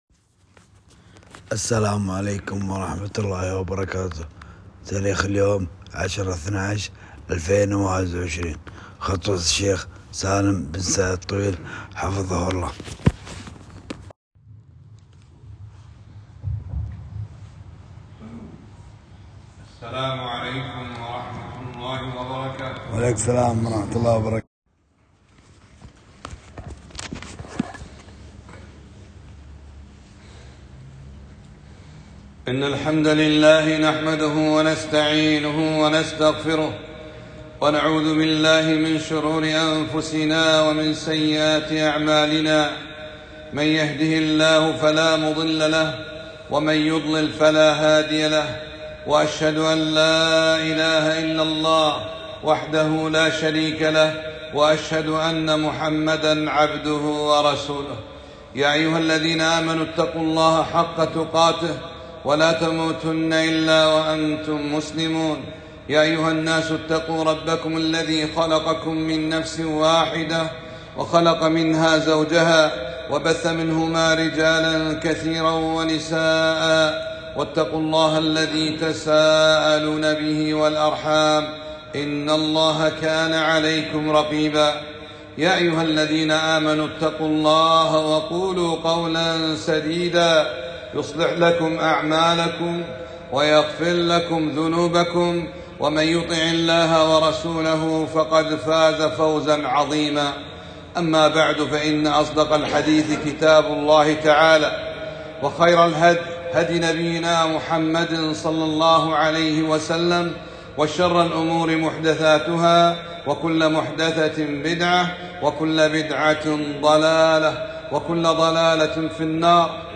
خطب الجمعة